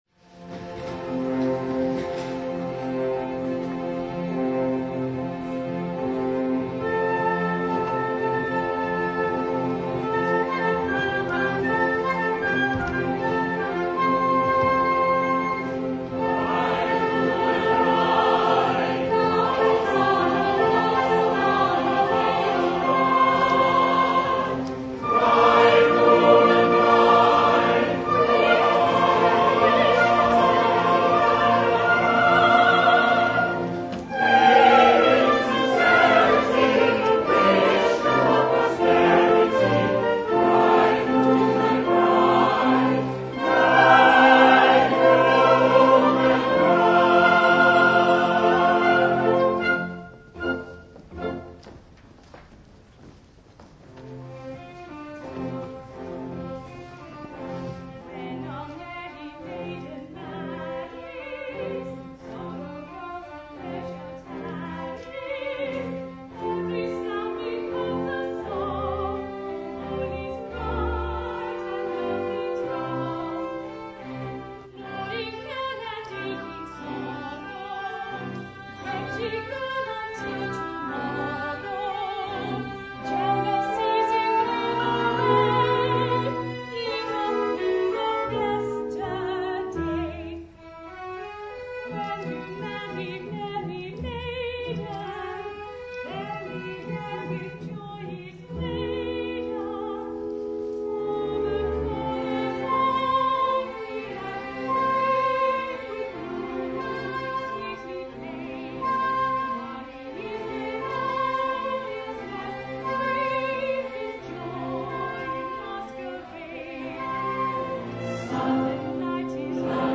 making live recordings of the society's productions.